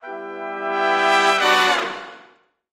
Music Effect; Big Jazz Band Brass Swells.